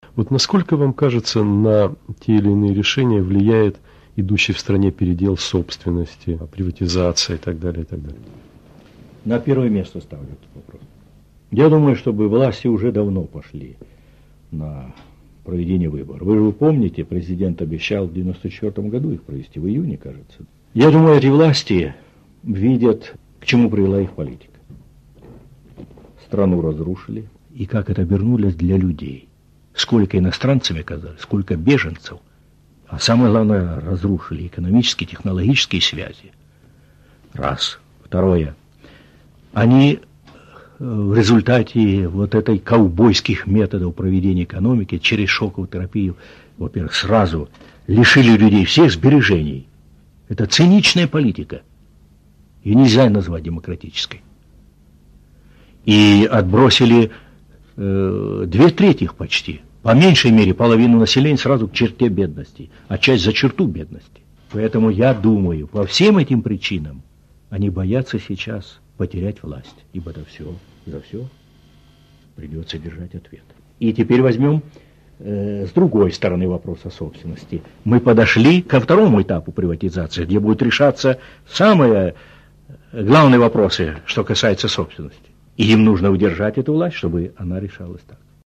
Первое интервью Михаила Горбачева Радио Свобода